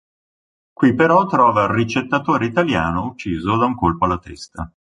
uc‧cì‧so
Uitgesproken als (IPA)
/utˈt͡ʃi.zo/